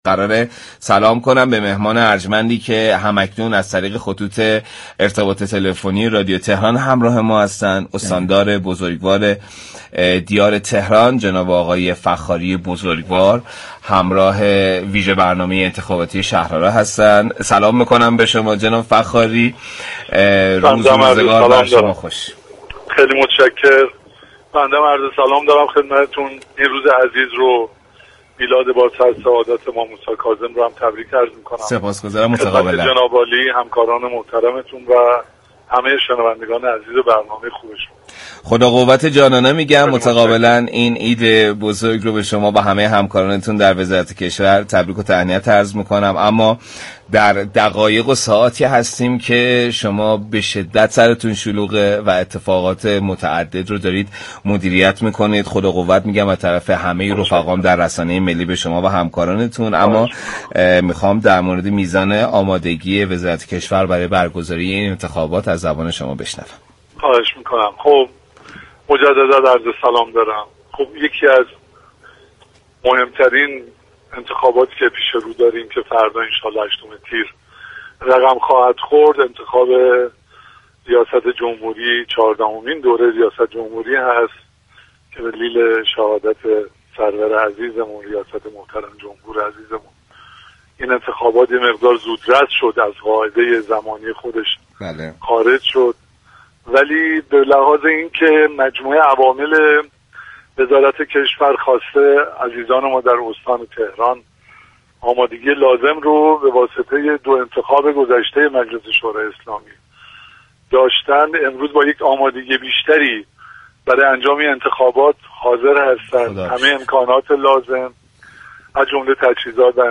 به گزارش پایگاه اطلاع رسانی رادیو تهران، علیرضا فخاری استاندار تهران در گفتگو با برنامه انتخاباتی «شهر آرا» كه به مناسبت برگزاری چهاردهمین دوره انتخابات ریاست جمهوری بر روی آنتن رادیو تهران می‌رود با بیان اینكگه انتخابات چهاردهمین دوره ریاست جمهوری یكی از مهمترین انتخابات كشور است كه در روز جمعه 8 تیر برگزار می‌شود.